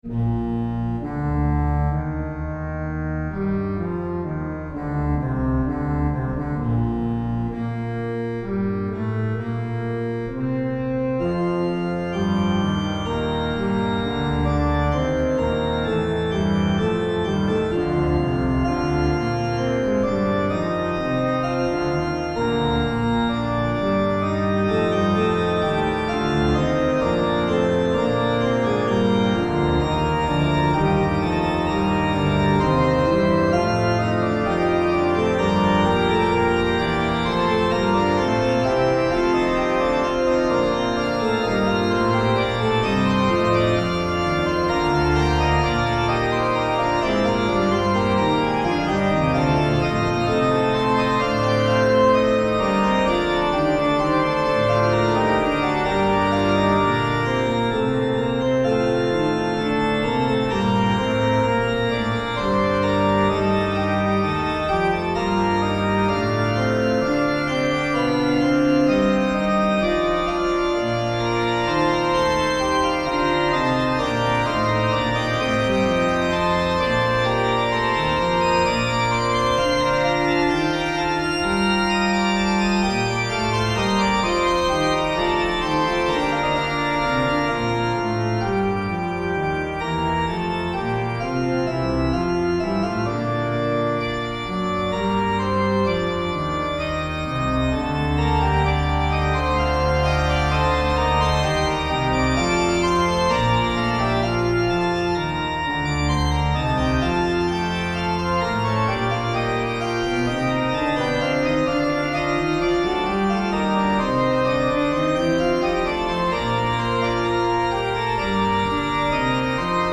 Getreu der Durchsetzung des alten Textes mit Latein wurde hier eine als archaisch geltende Satztechnik gewählt: der mit Trompetenklang vorgetragenen und von der Orgel begleiteten Melodie geht ein Vorspiel in Form einer Orgelfuge voraus – über ein Thema, das aus der Melodie abgeleitet ist.